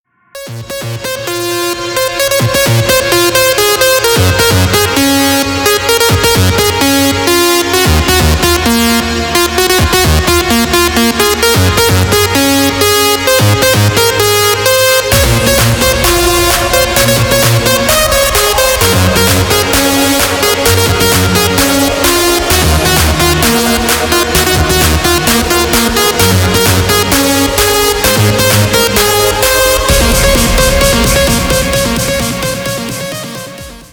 Громкая музыка без слов